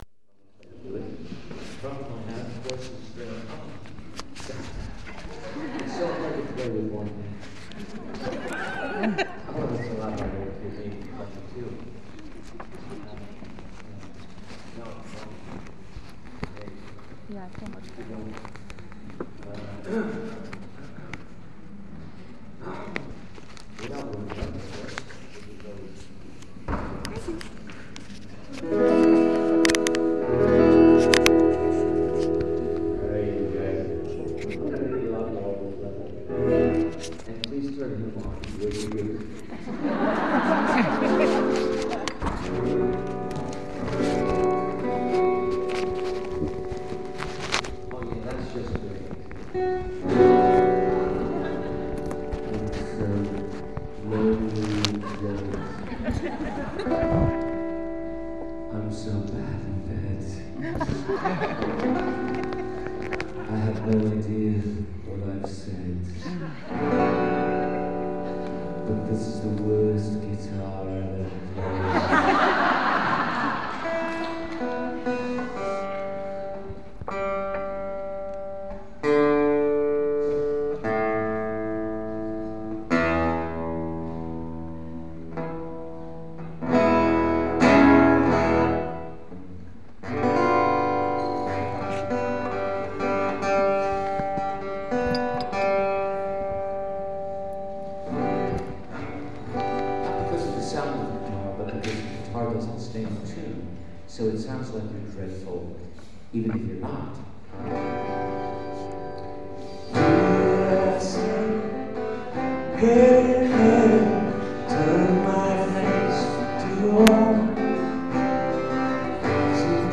David travelled to the Isle of Wight for a special one on one meet and greet with over 100 fans.
David spoke to the audience but didn’t perform any songs.